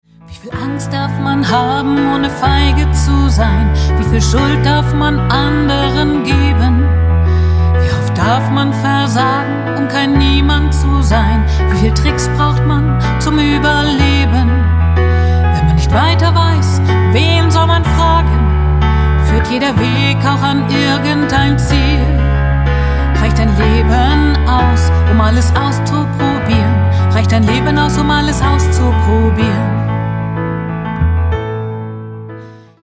Mezzosopran und Naturstimme